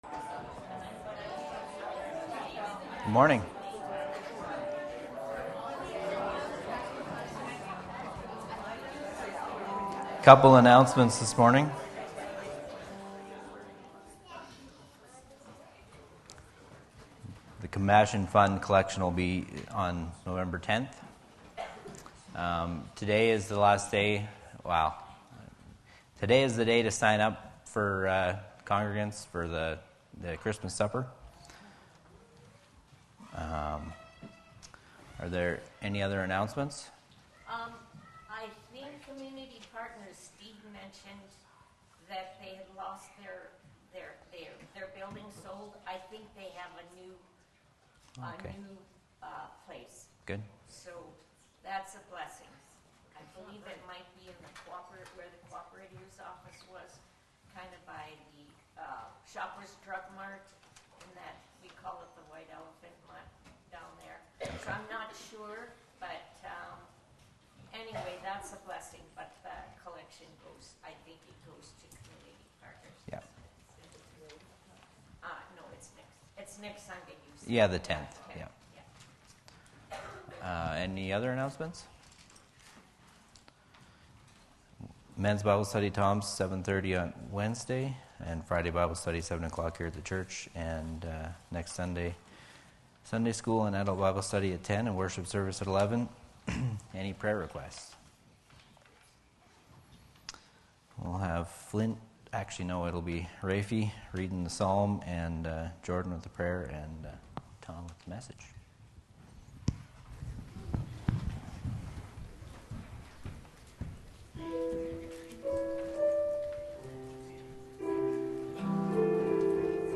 Sunday Worship
Sylvan Lake Apostolic Lutheran Church